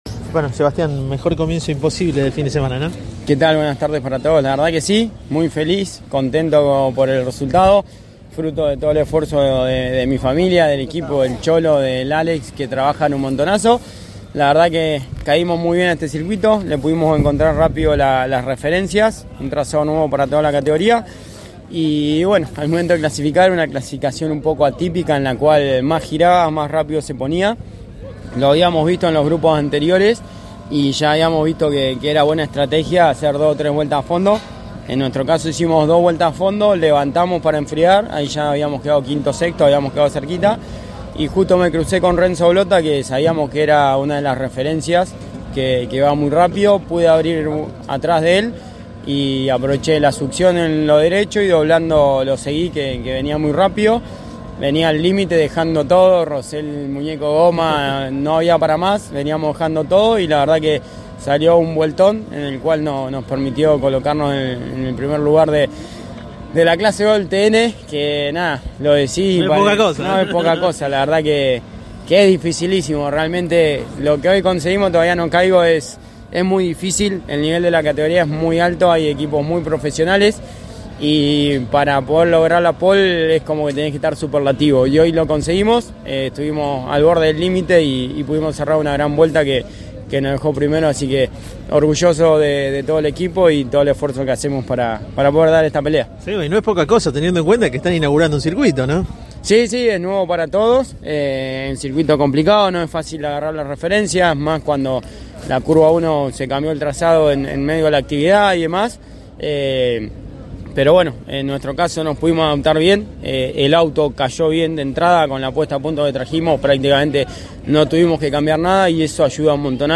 en exclusivo con CÓRDOBA COMPETICIÓN, por intermedio del enviado especial a dicha competencia